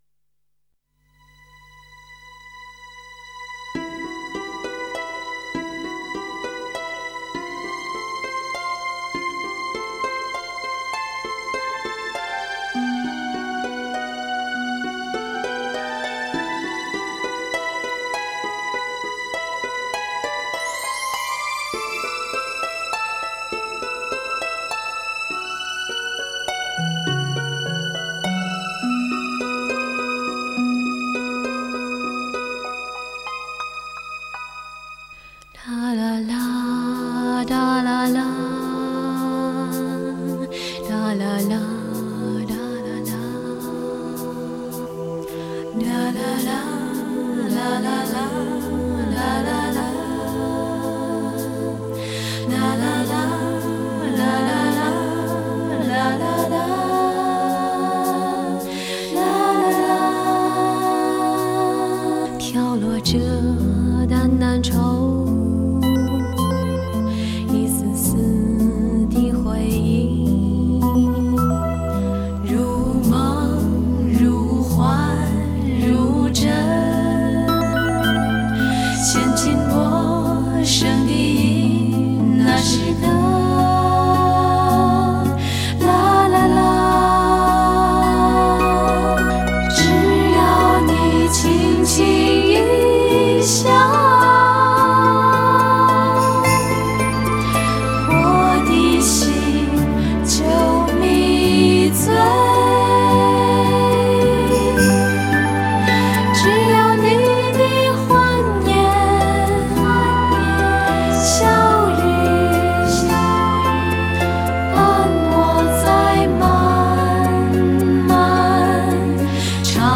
如何寻找一份寂静与解脱……清纯、甜美的歌声，